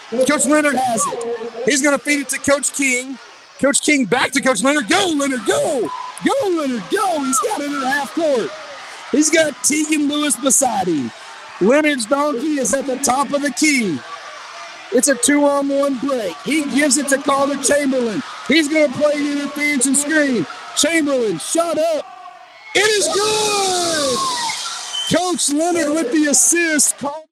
The hottest sport in Mountain Home Thursday night was basketball on donkey back as a packed house in the Junior High gym saw Harrison and Mountain Home face off in donkey basketball.